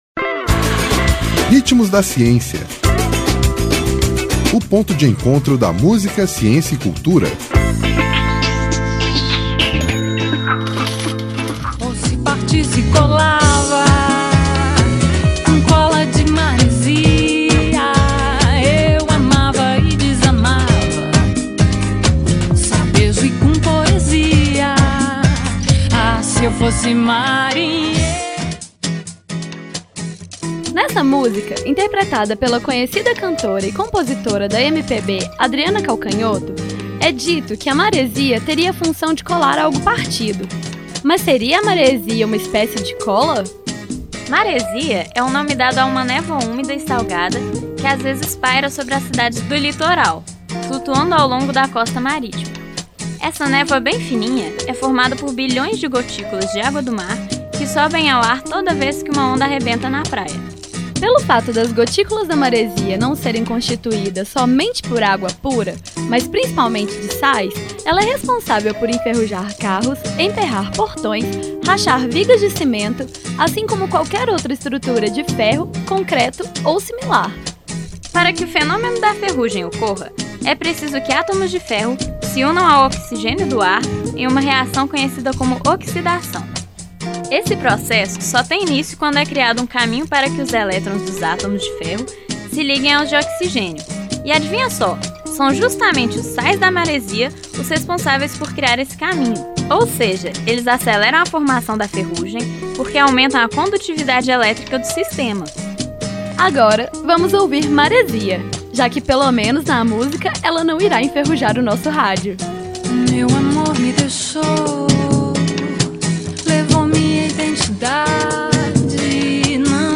Intérprete: Adriana Calcanhoto